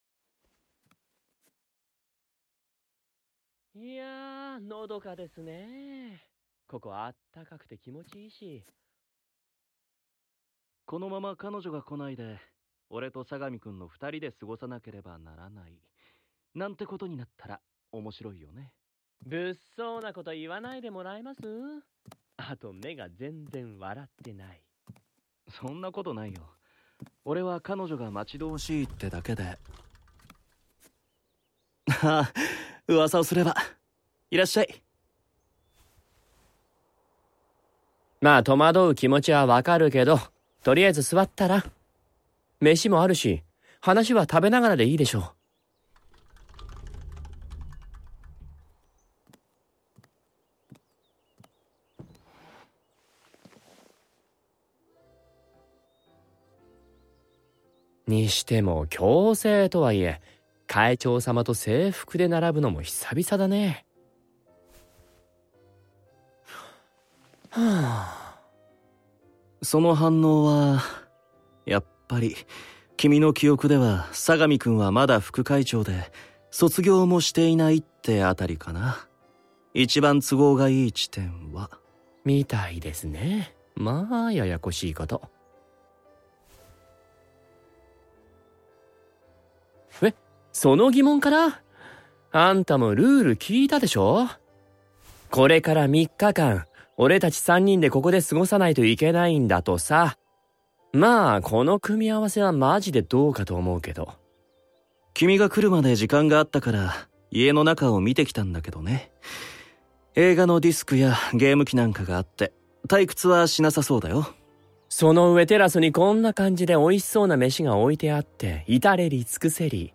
●18歳以上推奨　●一部ダミーヘッドマイクにて収録（現実パートのみ）